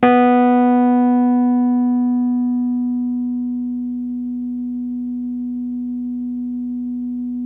RHODES CL0AR.wav